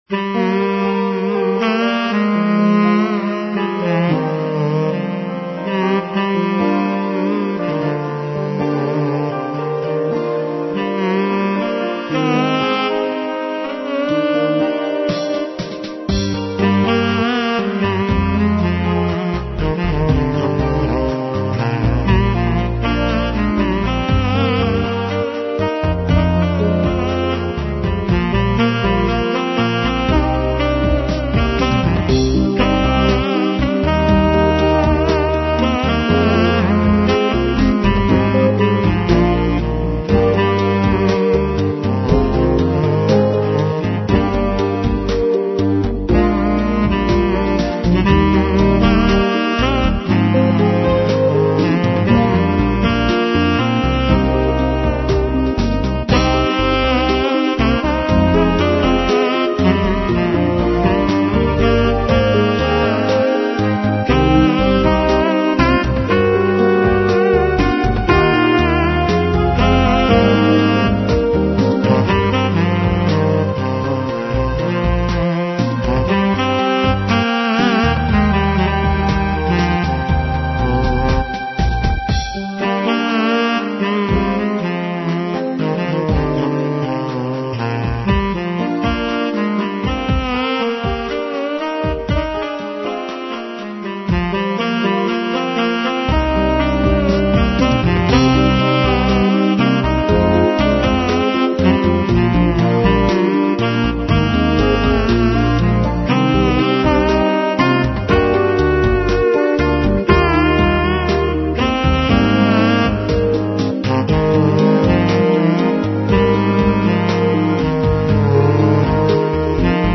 Relaxed Ballad with feel of Melancholy